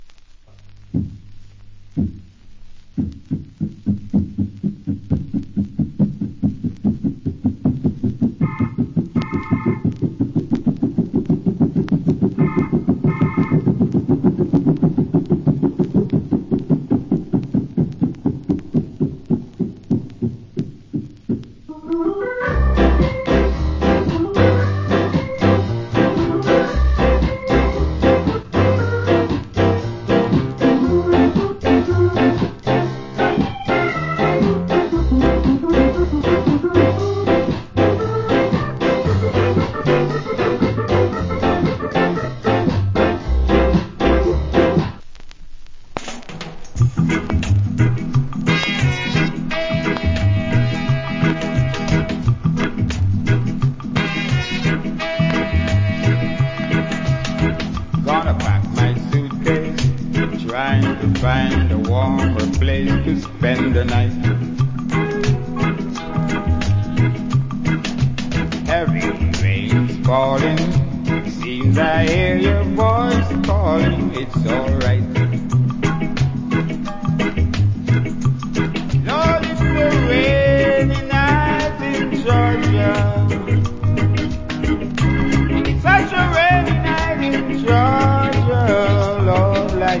Killer Organ Ska Inst.